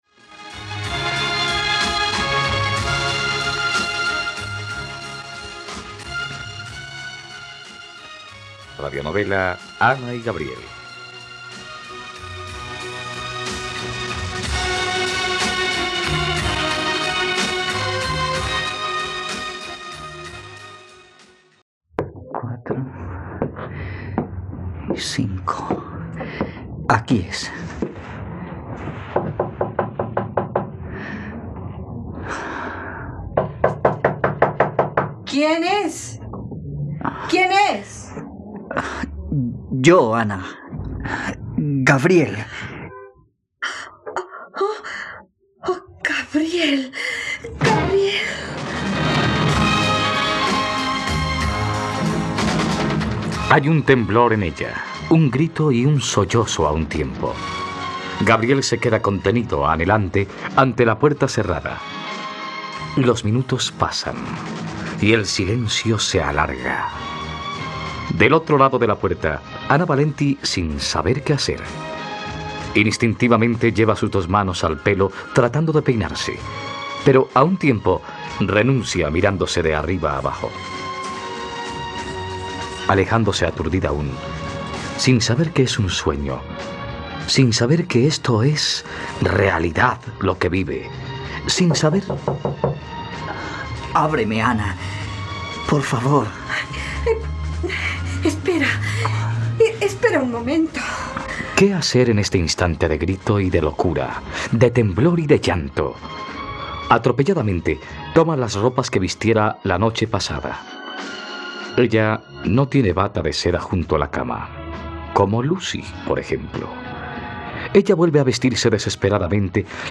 ..Radionovela. Escucha ahora el capítulo 114 de la historia de amor de Ana y Gabriel en la plataforma de streaming de los colombianos: RTVCPlay.